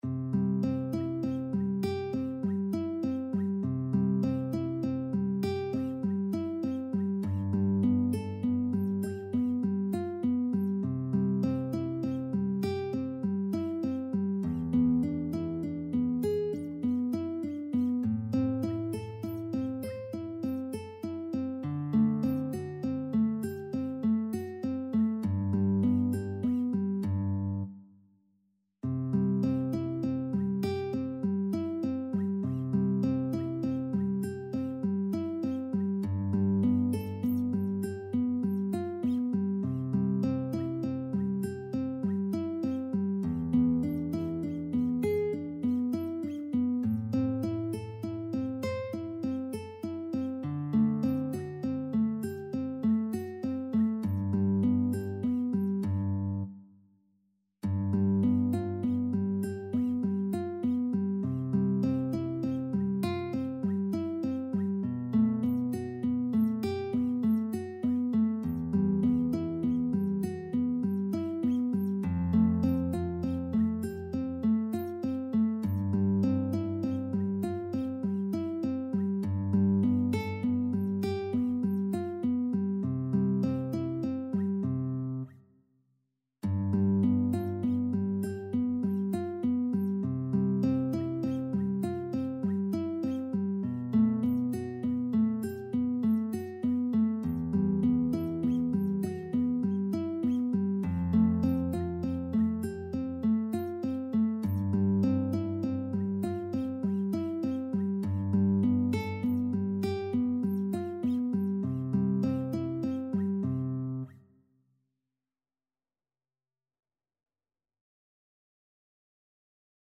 C major (Sounding Pitch) (View more C major Music for Guitar )
4/4 (View more 4/4 Music)
Easy Level: Recommended for Beginners with some playing experience
Guitar  (View more Easy Guitar Music)
Classical (View more Classical Guitar Music)